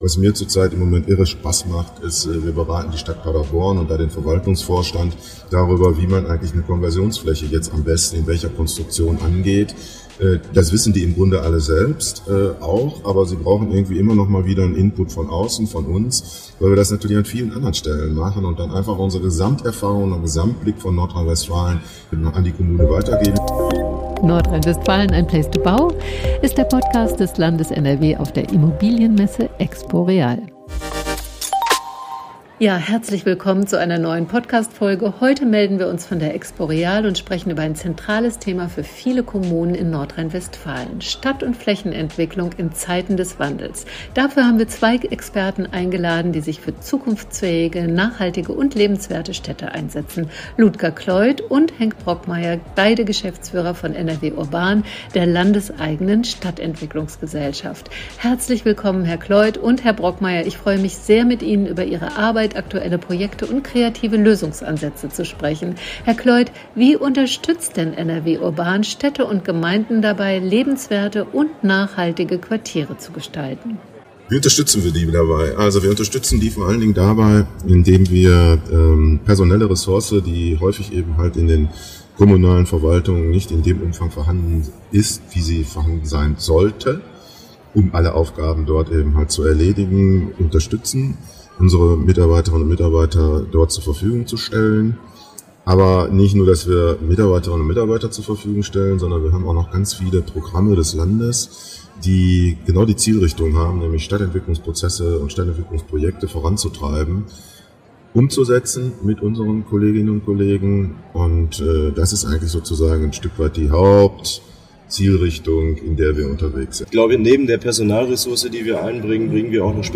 Städte im Wandel: Nachhaltige Quartiere gestalten mit NRW.URBAN ~ NRW "place to bau" - Messe-Podcast auf der EXPO REAL 2024 – On Air aus dem Zeitreisebus Podcast